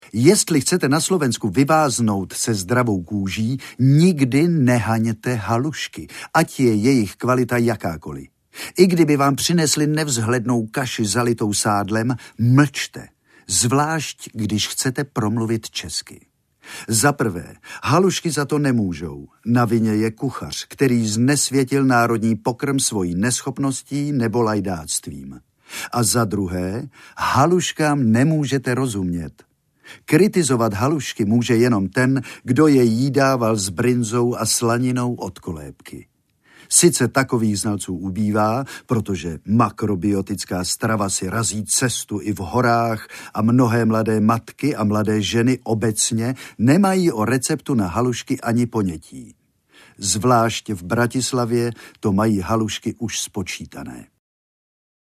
Jánošíci s těžkou hlavou audiokniha
Ukázka z knihy